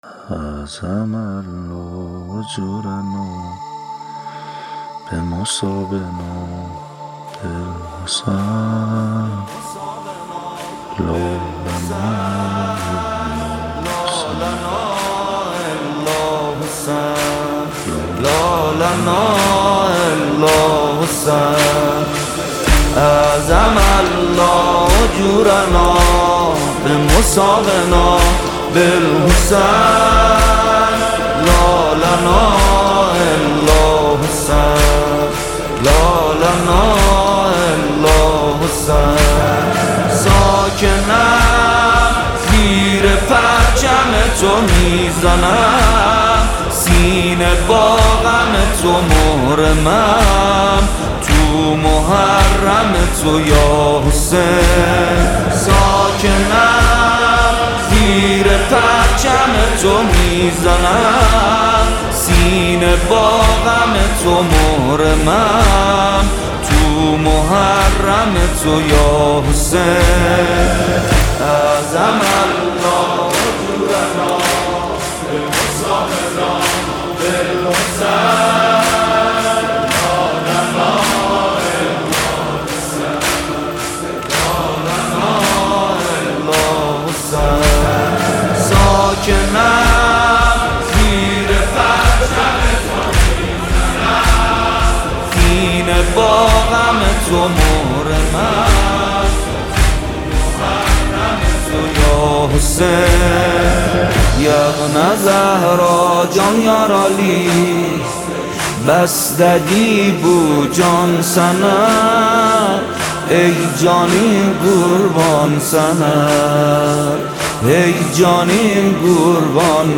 نماهنگ
هیئت ریحانه النبی (س) تهران